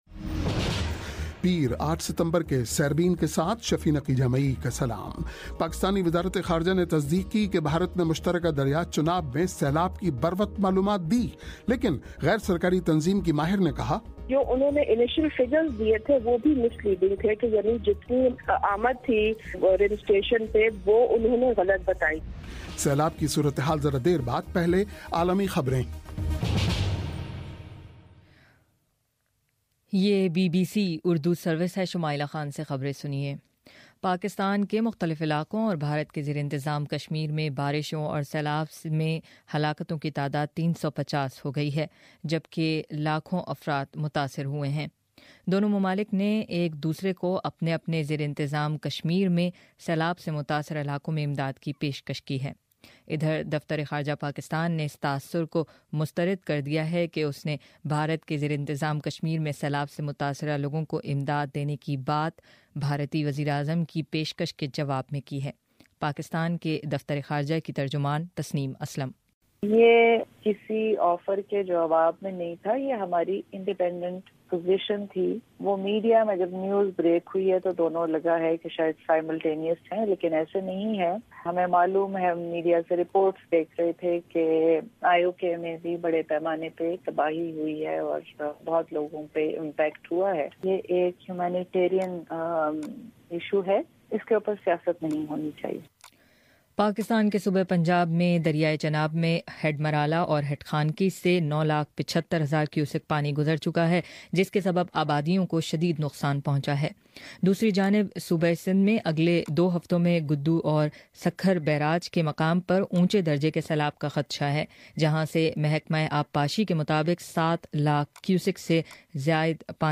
پیر 8 ستمبر کا سیربین ریڈیو پروگرام